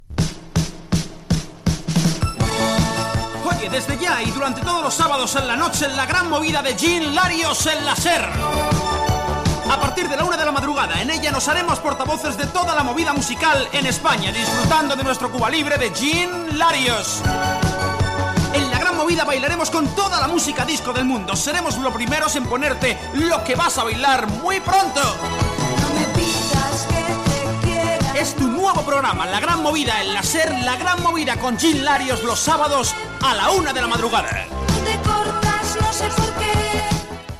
Dues promocions del programa